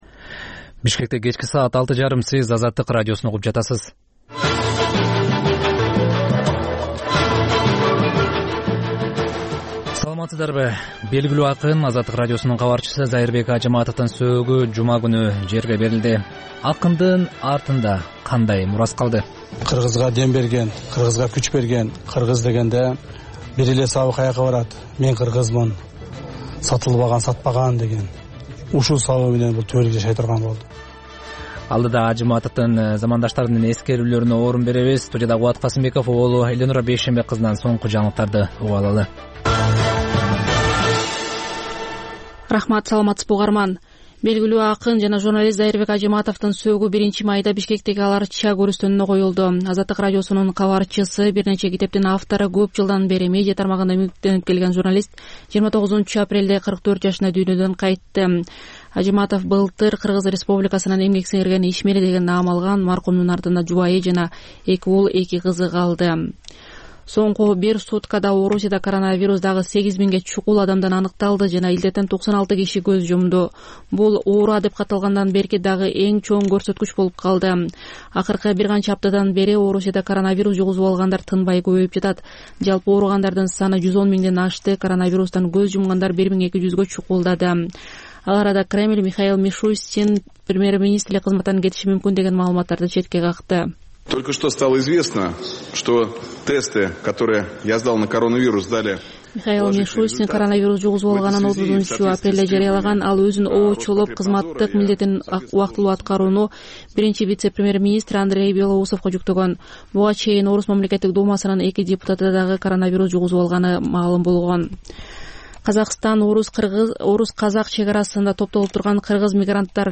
Бул үналгы берүү ар күнү Бишкек убакыты боюнча саат 18:30дан 19:00гө чейин обого түз чыгат.